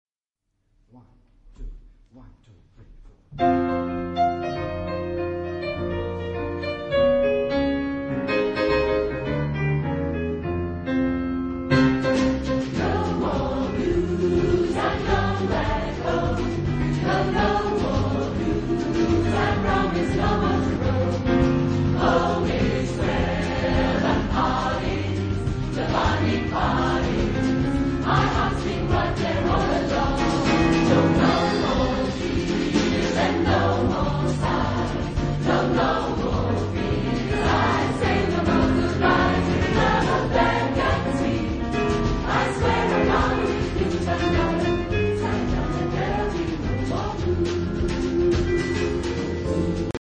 Genre-Style-Form: Samba ; Vocal jazz ; Pop music ; Secular
Type of Choir: SATB  (4 mixed voices )
Instruments: Piano (1)
Tonality: F minor
Discographic ref. : 7. Deutscher Chorwettbewerb 2006 Kiel